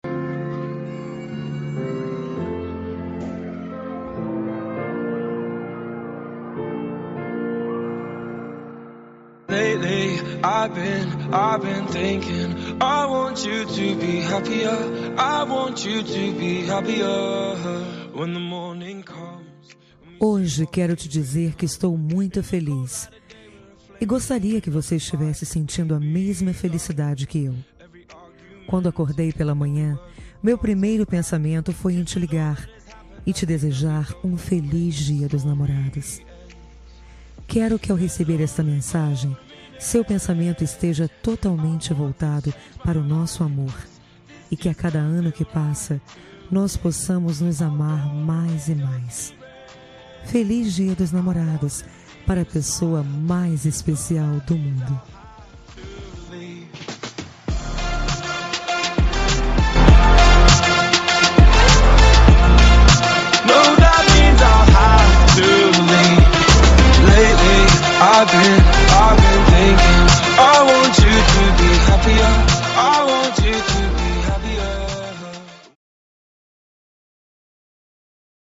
Dia dos Namorados – Para Namorado – Voz Feminina – Cód: 6858